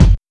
• Clean Kick Single Hit G Key 550.wav
Royality free bass drum sound tuned to the G note. Loudest frequency: 221Hz
clean-kick-single-hit-g-key-550-Wyo.wav